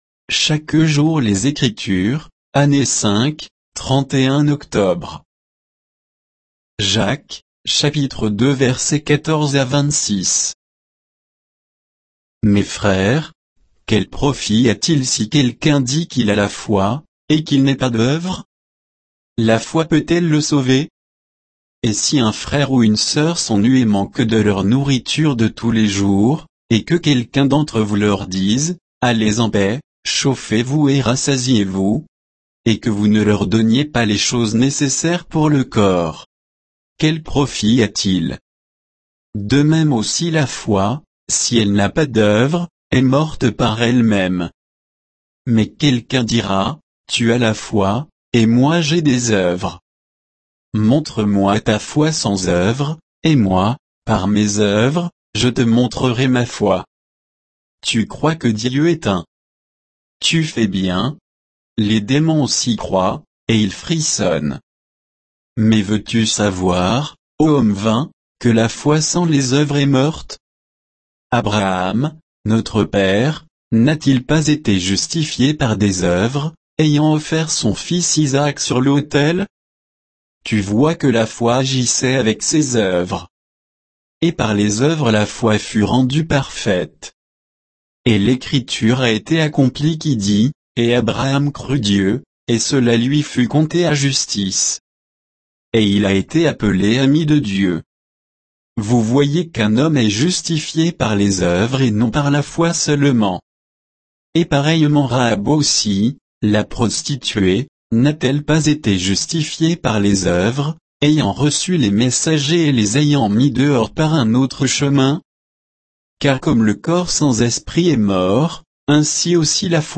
Méditation quoditienne de Chaque jour les Écritures sur Jacques 2